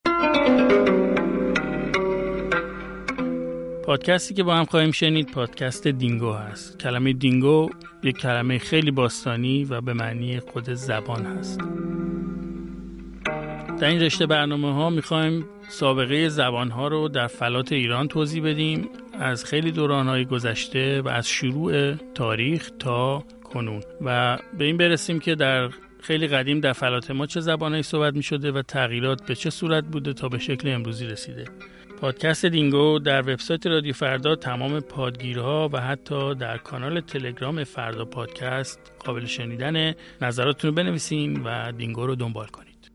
در دینگو نمونه‌هایی از زبان‌‌ها و حتی موسیقی‌های باستانی را خواهید شنید، زبان‌هایی که بعضی از آنها منقرض شده‌‌اند و بعضی تغییر شکل پیدا کردند و به دوران معاصر ما رسیده‌‌اند.